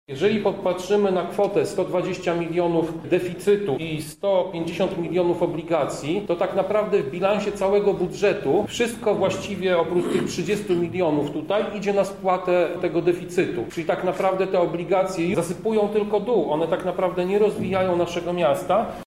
O zobowiązaniach finansowych mówi przewodniczący Klubu Radnych Prawa i Sprawiedliwości Tomasz Pitucha.